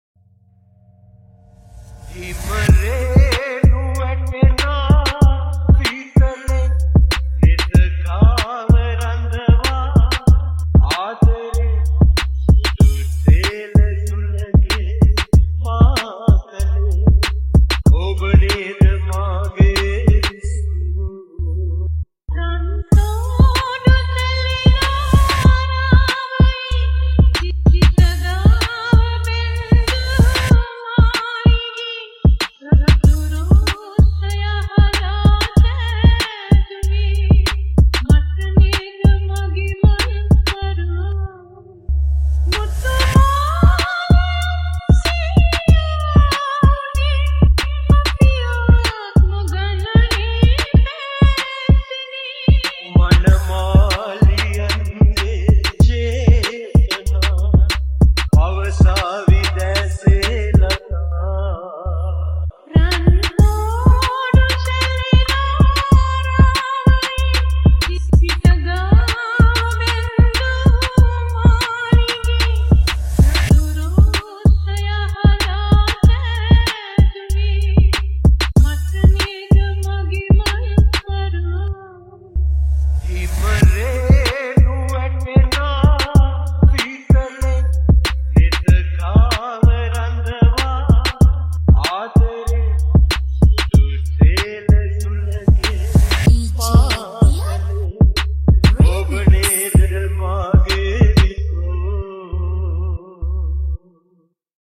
High quality Sri Lankan remix MP3 (1.7).